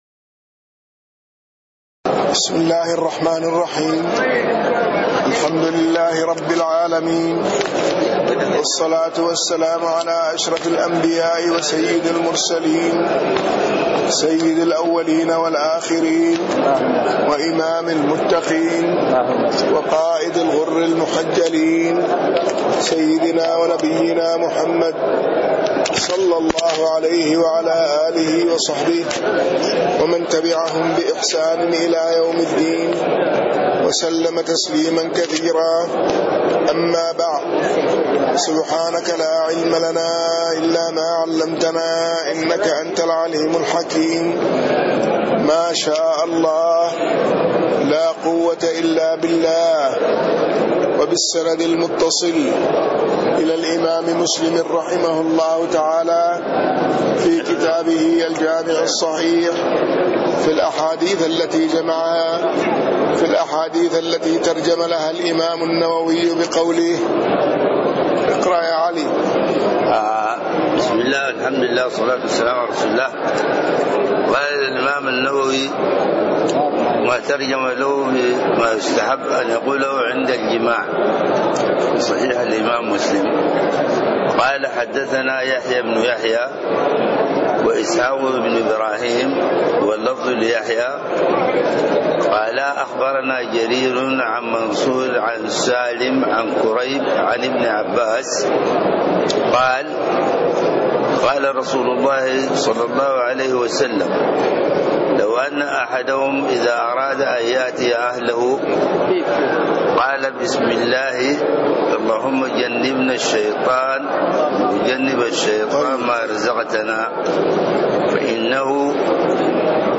تاريخ النشر ٢١ جمادى الآخرة ١٤٣٤ هـ المكان: المسجد النبوي الشيخ